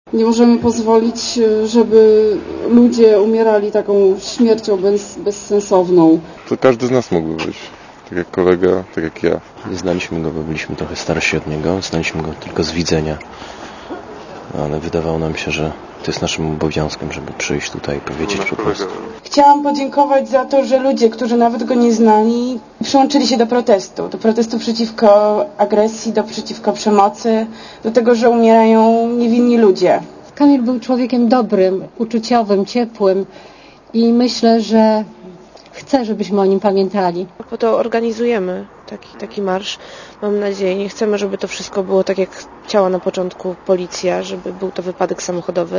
Komentarz audio
marszwarszawa.mp3